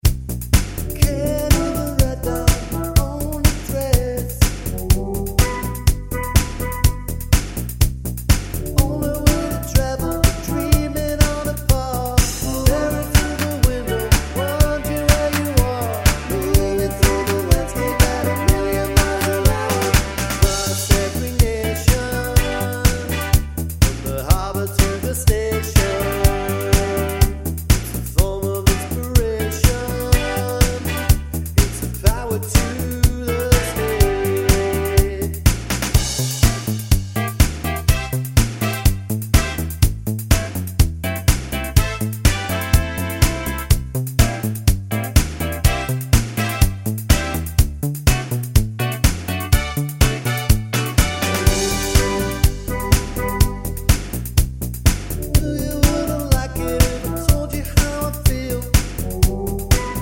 Pop (1980s) 3:15 Buy £1.50